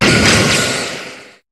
Cri de Magearna dans Pokémon HOME.